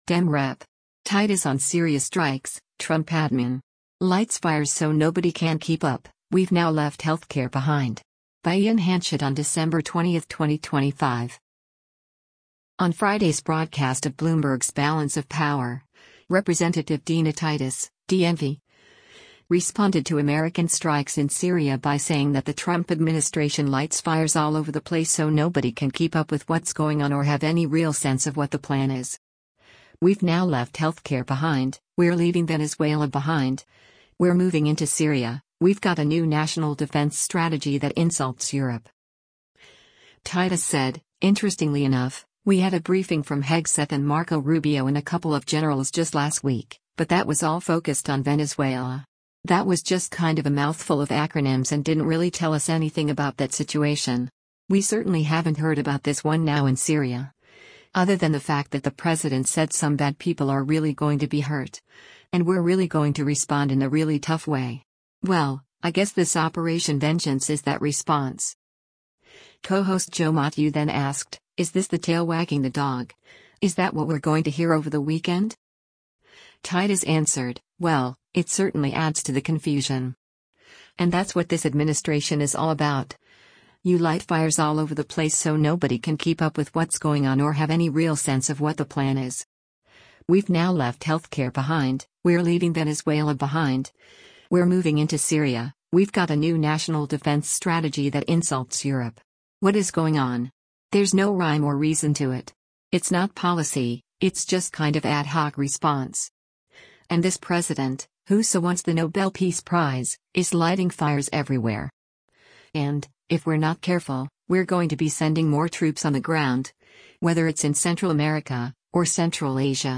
On Friday’s broadcast of Bloomberg’s “Balance of Power,” Rep. Dina Titus (D-NV) responded to American strikes in Syria by saying that the Trump administration lights “fires all over the place so nobody can keep up with what’s going on or have any real sense of what the plan is. We’ve now left healthcare behind, we’re leaving Venezuela behind, we’re moving into Syria, we’ve got a new national defense strategy that insults Europe.”